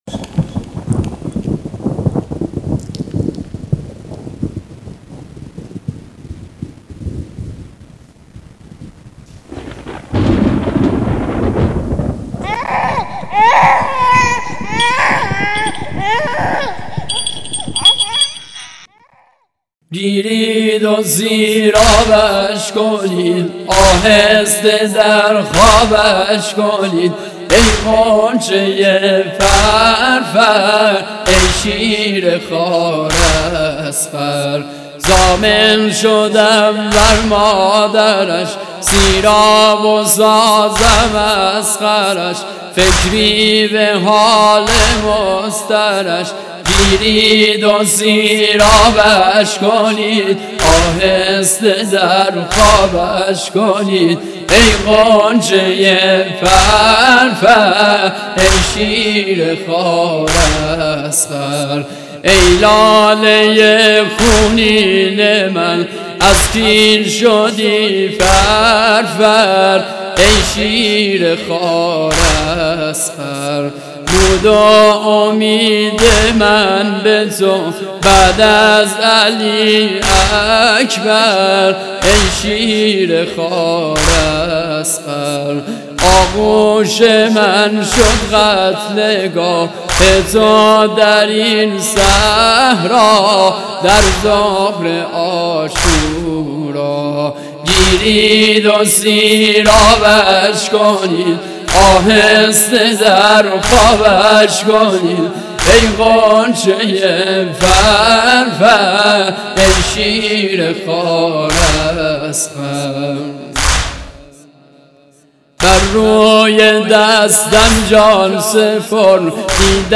نوحه محرم 97